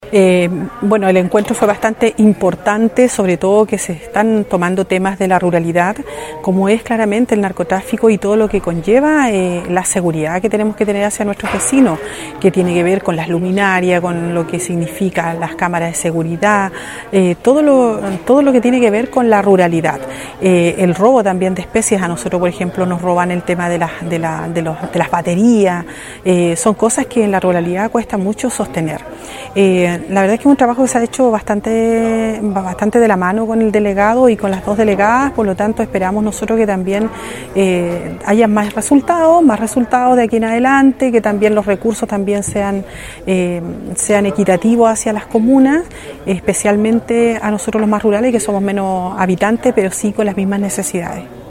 La Alcaldesa de Río Hurtado, Juan Olivares, destacó que
CONSEJO-SEGURIDAD-Juana-Olivares-Alcaldesa-Rio-Hurtado.mp3